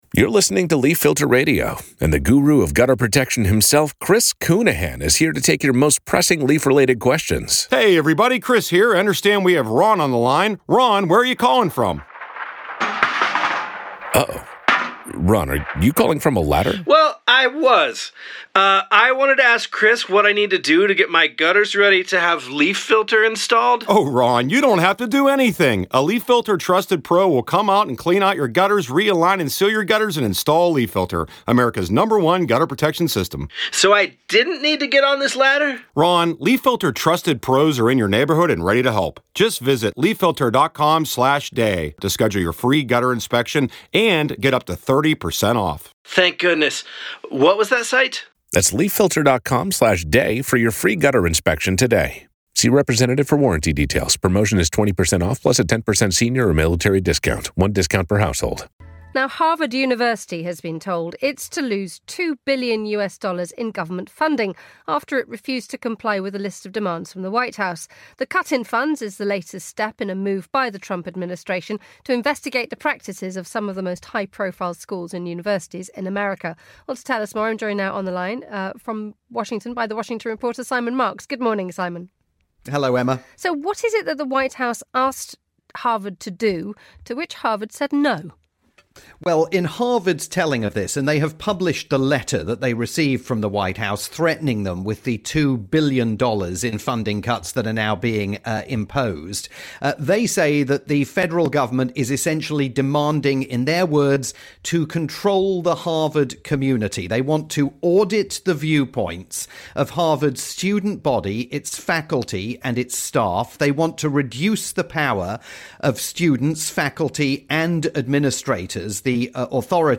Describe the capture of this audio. live update for Monocle Radio about Harvard University's decision to challenge Trump's decision to freeze more than $2 billion in federal funding for the storied institution.